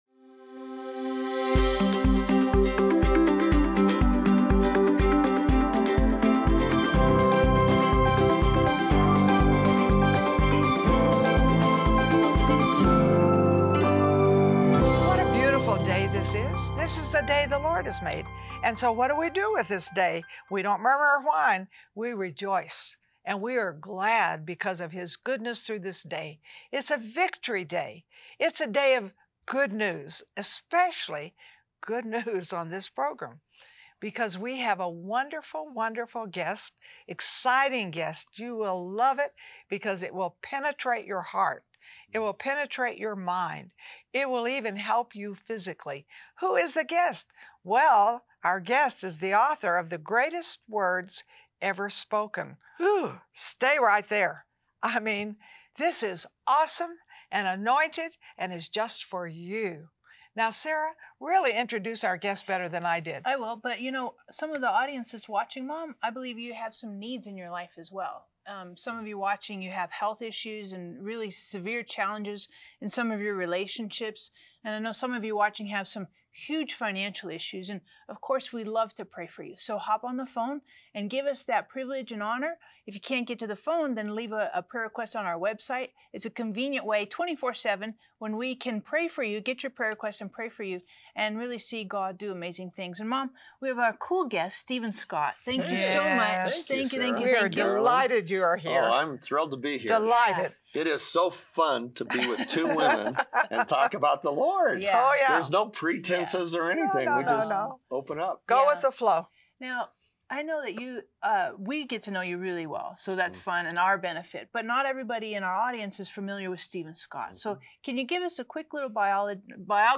Have you ever wanted to know everything Jesus said about you, your life, and everything else? Don’t miss this wonderful interview